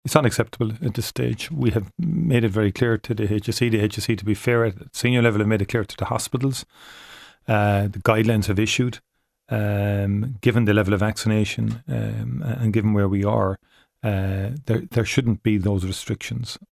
Micheal Martin says it shouldn’t be happening: